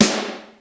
Index of /90_sSampleCDs/300 Drum Machines/Akai XR-10/Snaredrums
Snaredrum-05.wav